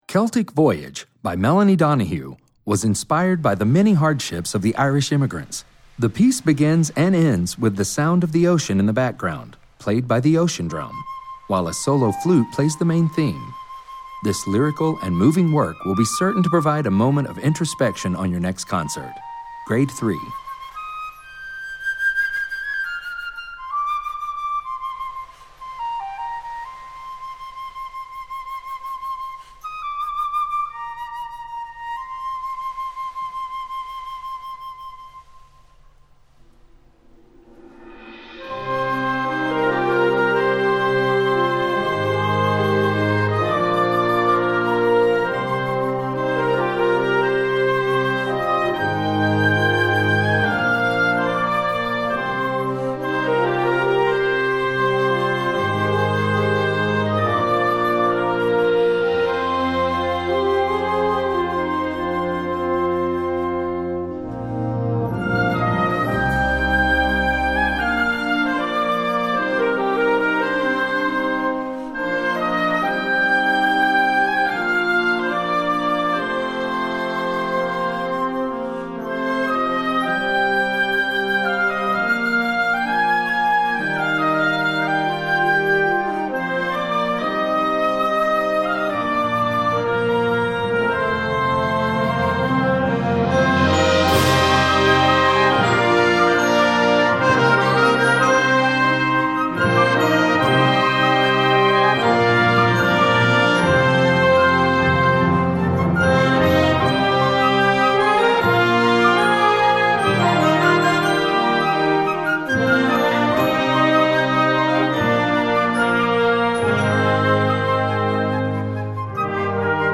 Gattung: Werk für Jugendblasorchester
3:50 Minuten Besetzung: Blasorchester PDF